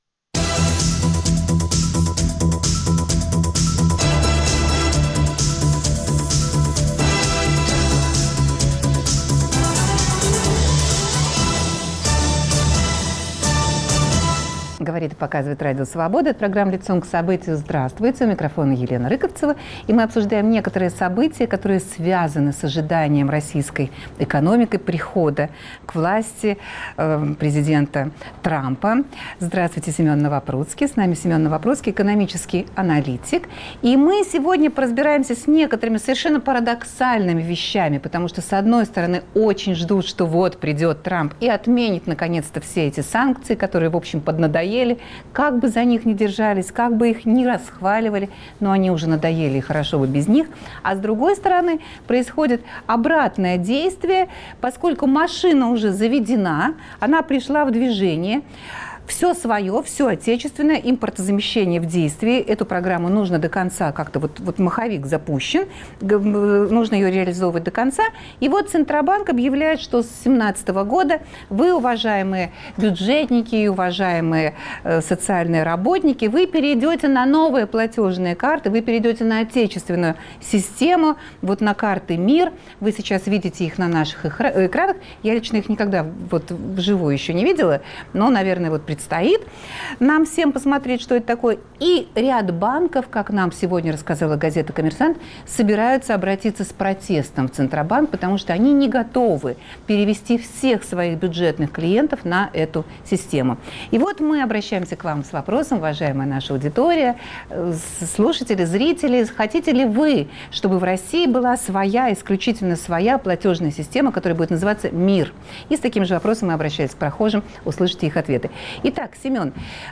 Россия мечтает об отмене санкций и при этом продолжает накладывать на себя новые наказания. Гость студии - экономический журналист